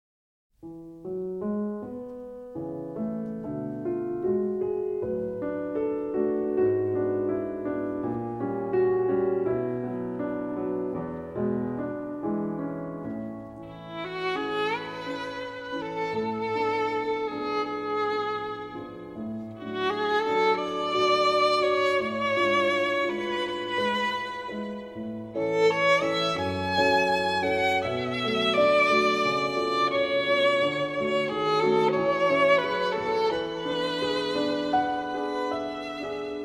～女性作曲家の珠玉の作品をファミリーで演奏～
彼女の作品は、叙情的で表現豊かです。